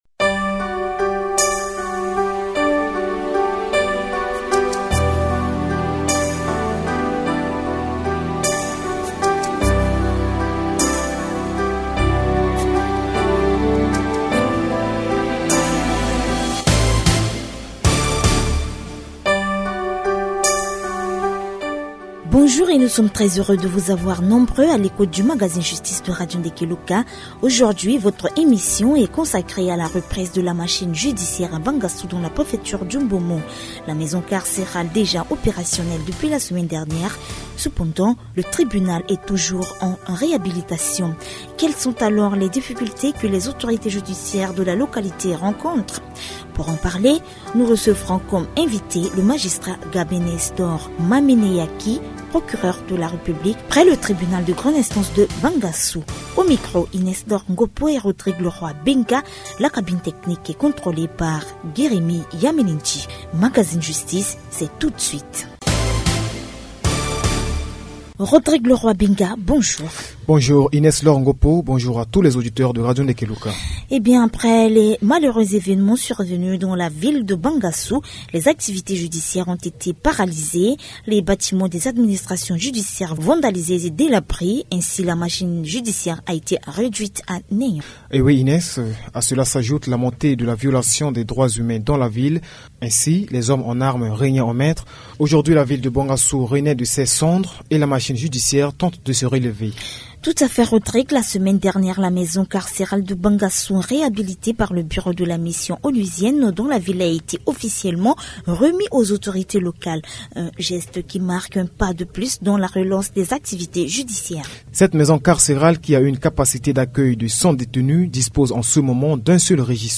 Le magistrat, Gabin Nestor Mameneyaki, Procureur de la République près le Tribunal de Grande Instance de Bangassou, explique que la machine judiciaire peine à fonctionner à cause du délabrement des infrastructures. Le Tribunal de Grande Instance est en cours de réhabilitation, obligeant les magistrats à ne pas disposer de structures pour dire le droit. A cela s’ajoute un autre problème, le manque des officiers de police judiciaire qui ne facilite pas la tâche au niveau du parquet.